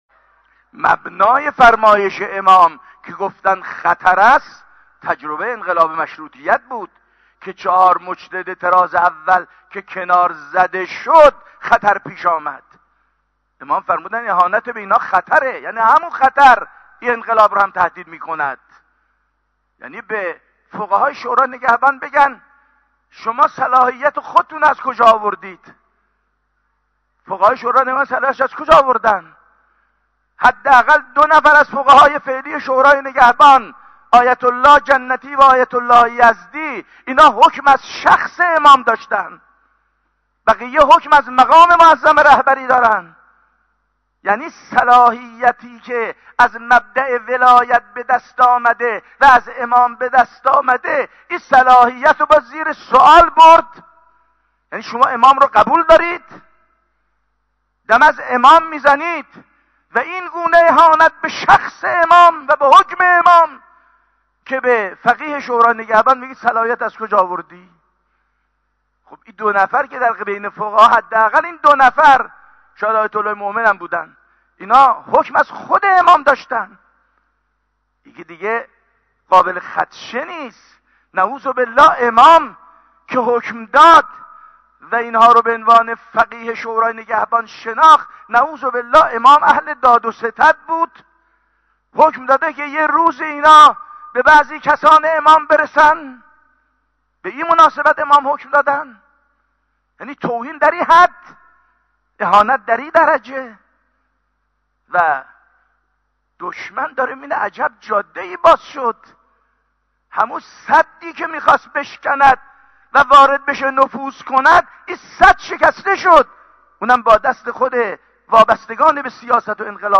خطبه.mp3